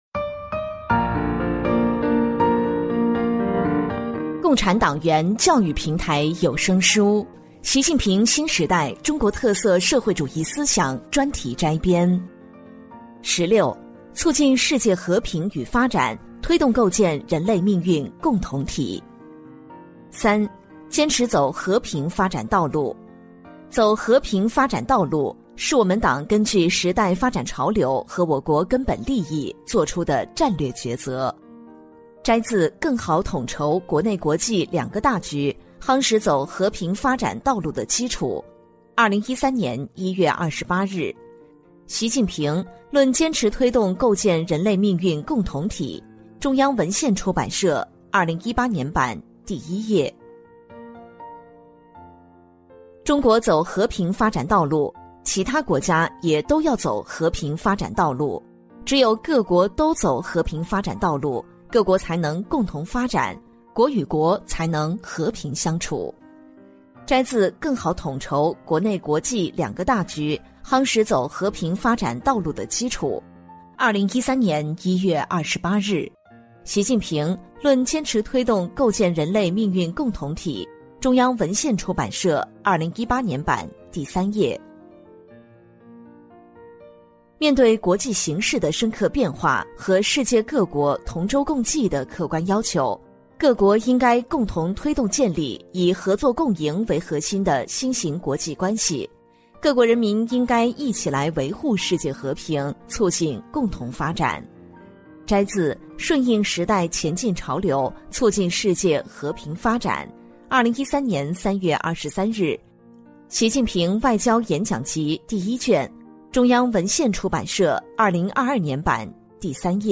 聊城机关党建 - 《习近平新时代中国特色社会主义思想专题摘编》 - 主题教育有声书 《习近平新时代中国特色社会主义思想专题摘编》（75）